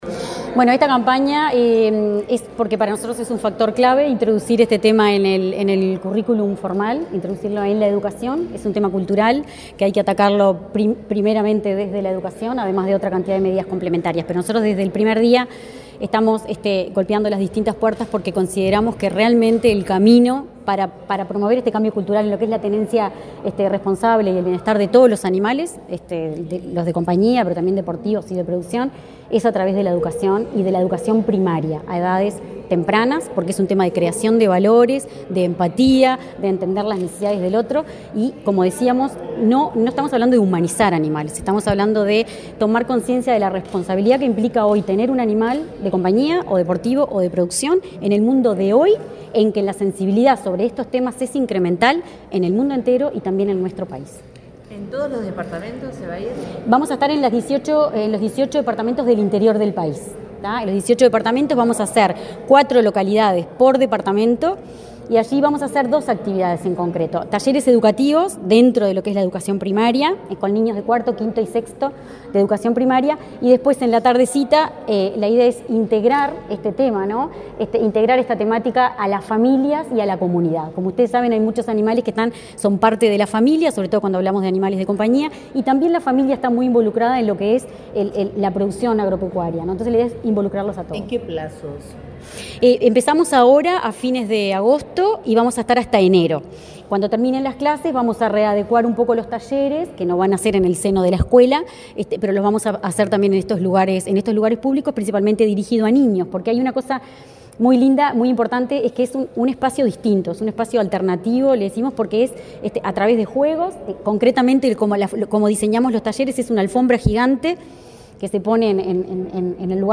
Declaraciones de la directora del Instituto Nacional de Bienestar Animal , Marcia del Campo
Declaraciones de la directora del Instituto Nacional de Bienestar Animal , Marcia del Campo 12/08/2024 Compartir Facebook X Copiar enlace WhatsApp LinkedIn La directora del Instituto Nacional de Bienestar Animal , Marcia del Campo, dialogó con la prensa, luego de participar del acto de presentación de la campaña de educación y sensibilización de bienestar animal, tenencia, y producción agropecuaria responsable.